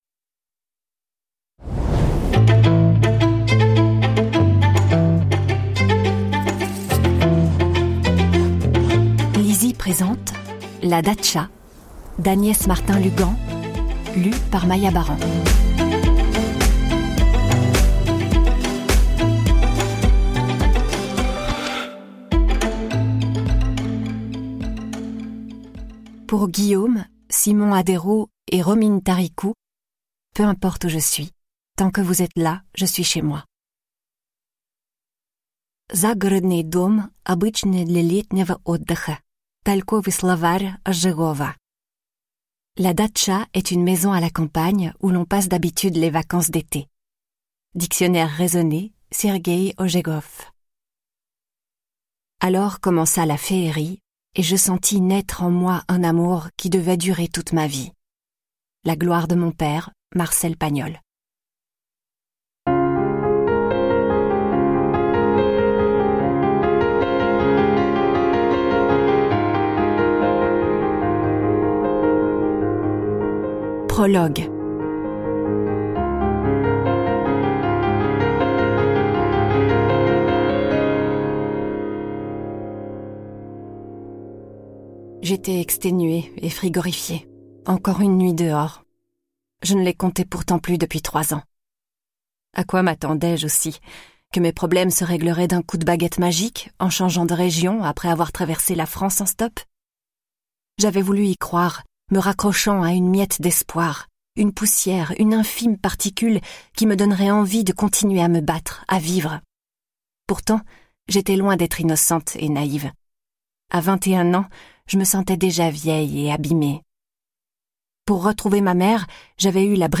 Click for an excerpt - La Datcha de Agnès MARTIN-LUGAND